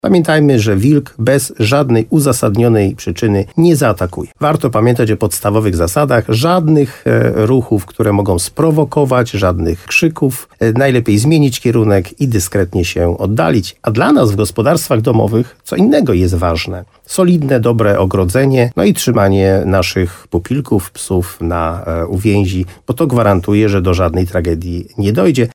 Wójt Jerzy Wałęga apeluje o ostrożność, ale jednocześnie tonuje nastroje w tej kwestii.